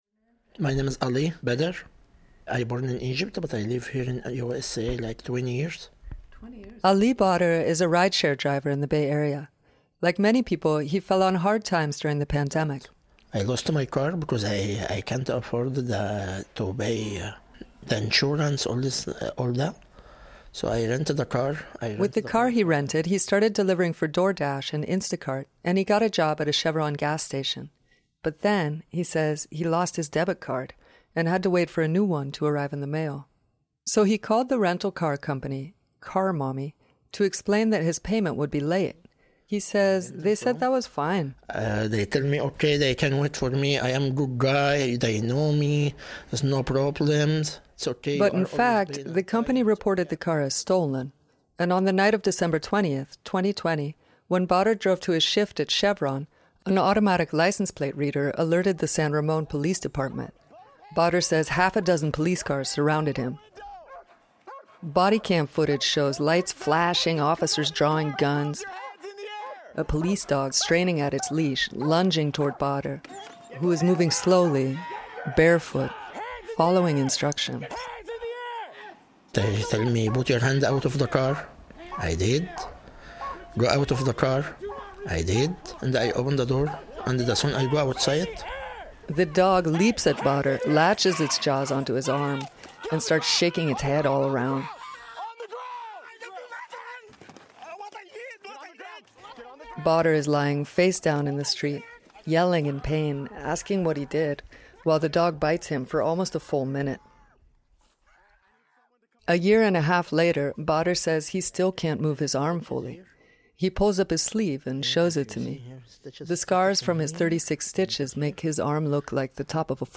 Please note that this story has descriptions and audio of violent police dog attacks.